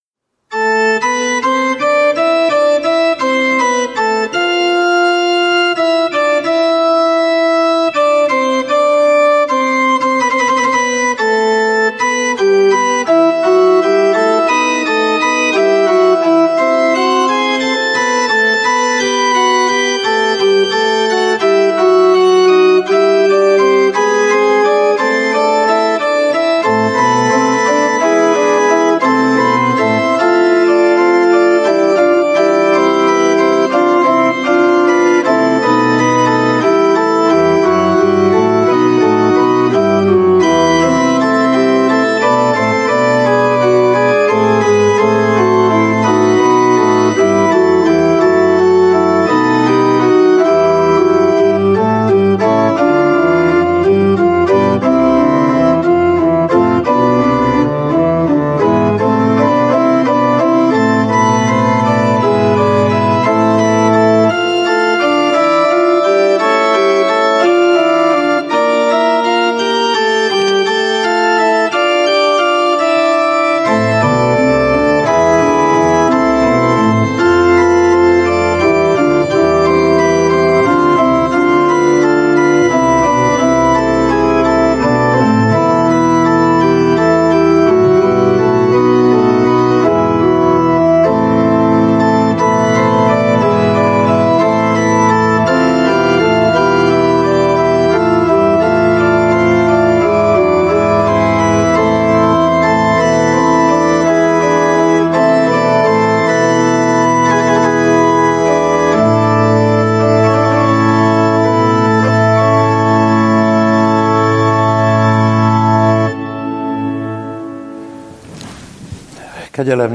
Bogárdi Szabó István 2020. november 29. advent első vasárnapja (online)